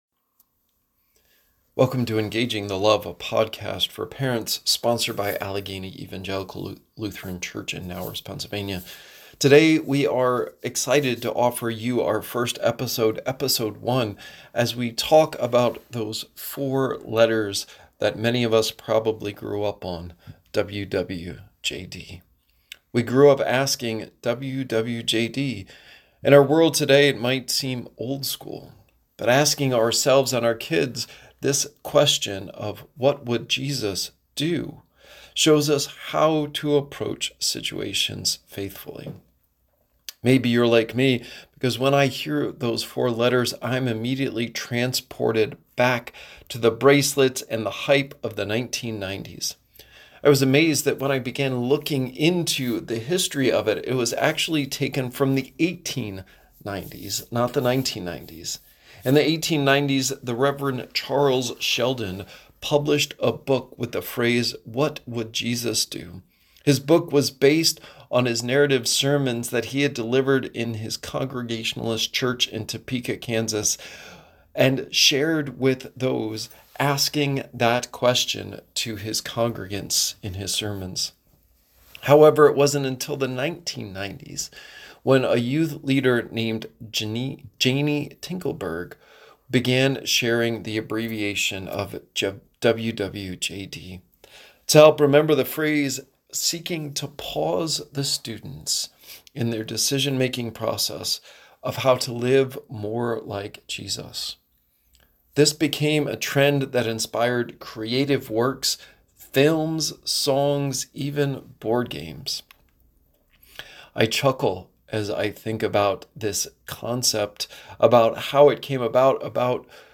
Sermons | Allegheny Evangelical Lutheran Church